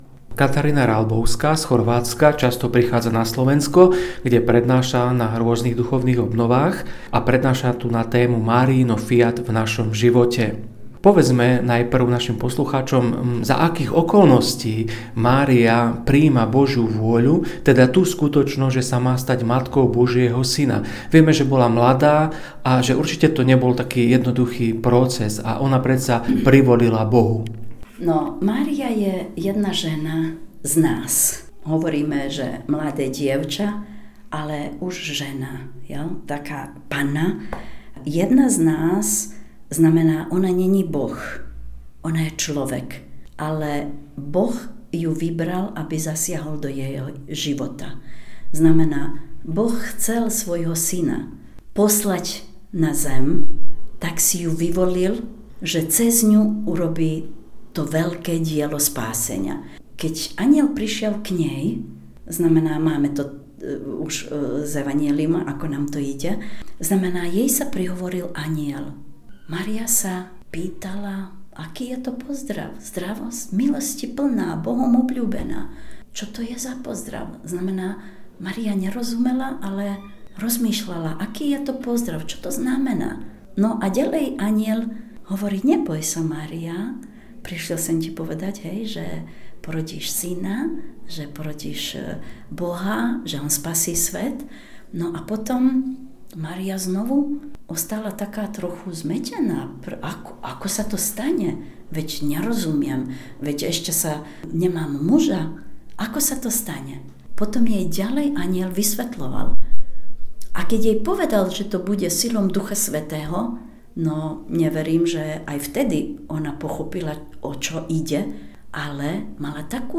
Duchovné cvičenia KOČ 2024
V dňoch 31. mája až 2. júna sa konali v Dome pútnika pri Diecéznej svätyni Božieho milosrdenstva v Smižanoch duchovné cvičenia Kruciáty oslobodenia človeka s témou - Máriino fiat v našom živote.